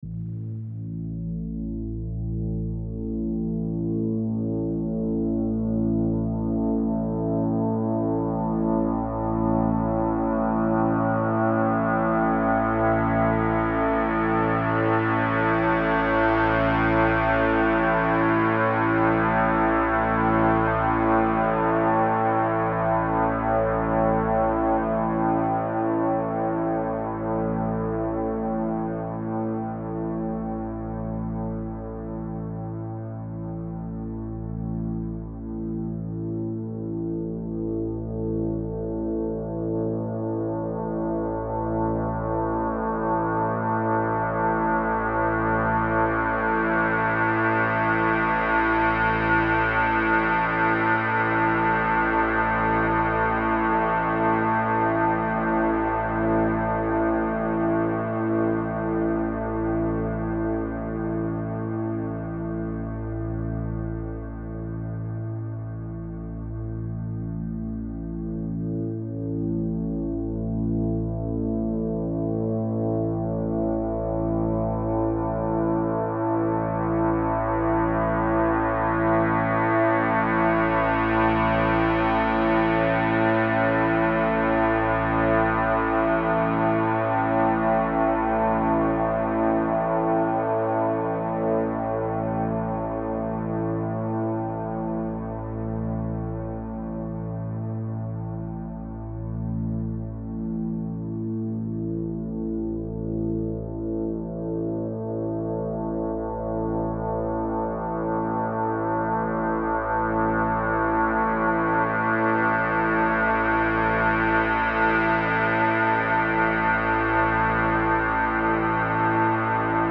396 Hz